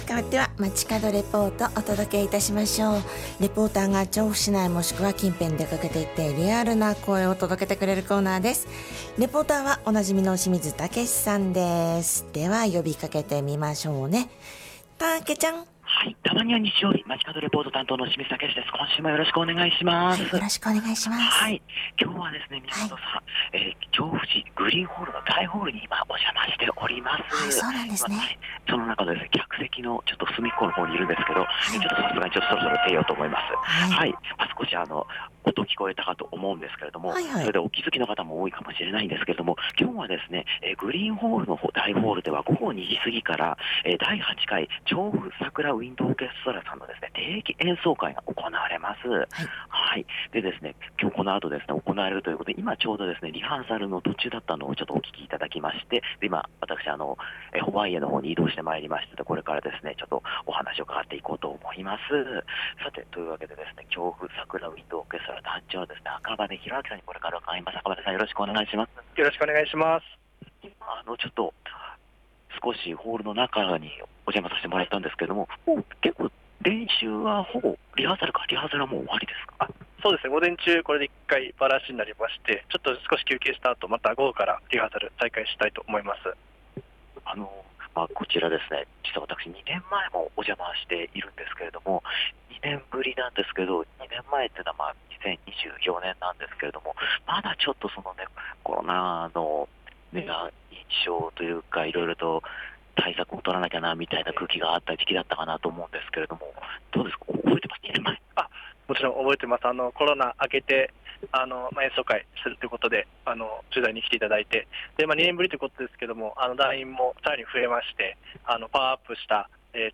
長年駅周辺のシンボルでもある調布市グリーンホールの2階、大ホールホワイエからお届けしました。